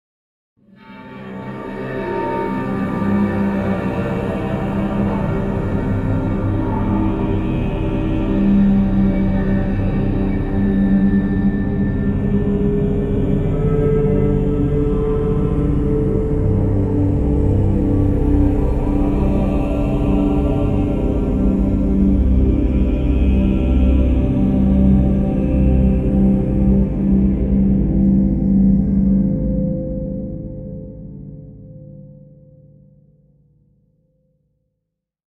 Eerie Cinematic Background Sound Effect
Description: Eerie cinematic background sound Effect. Terrifying, suspenseful deep background sound. Features a deep male choir and atmospheric, tense synth tones.
Eerie-cinematic-background-sound-effect.mp3